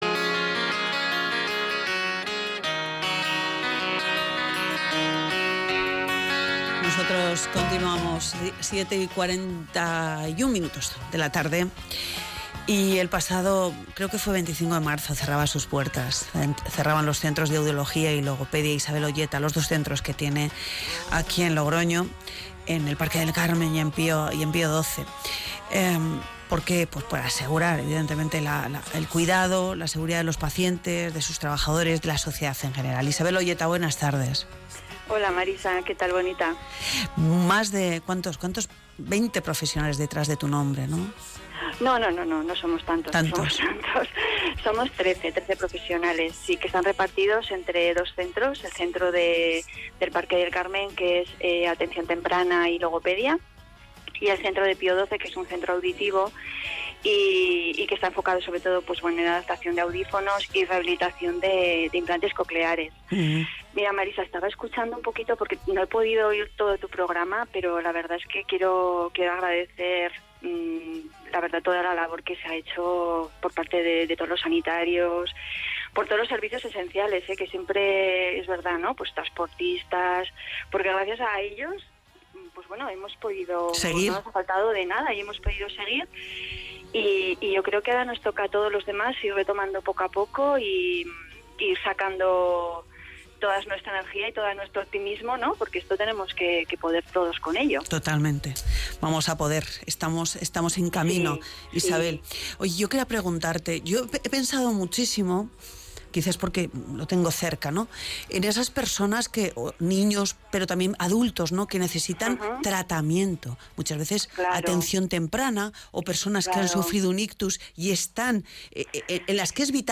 Colaboraciones en radio